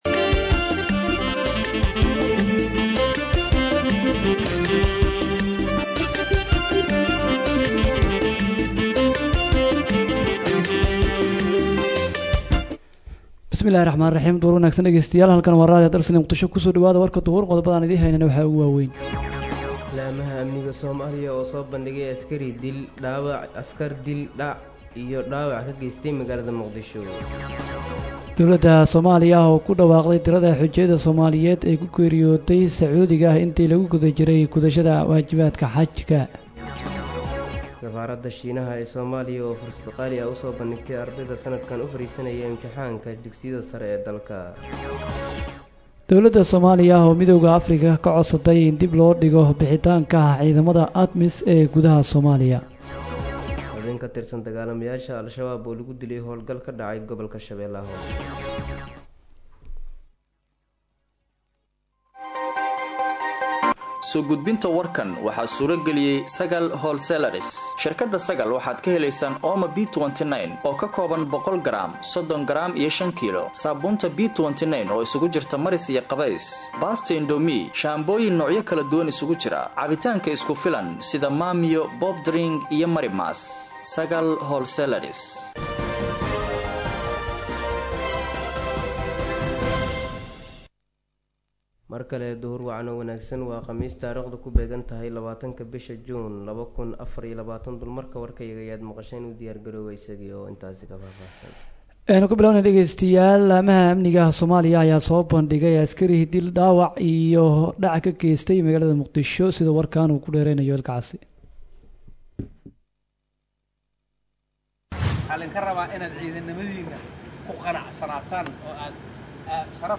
Dhageyso:-Warka Duhurnimo Ee Radio Dalsan 20/06/2024